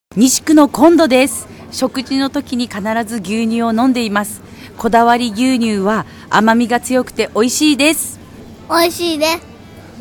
試飲いただいたお客様の生の声
リンクをクリックするとこだわり牛乳を試飲いただいた皆様からの感想を聞くことができます。
4月1日（火）15:00～18:00　スーパーアークス 菊水店
お客様の声5